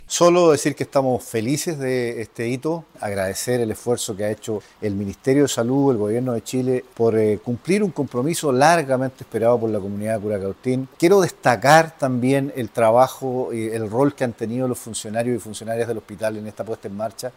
Por su parte, el alcalde de Curacautín, Hugo Vidal, valoró el hito y el trabajo interinstitucional que permitió concretar esta obra.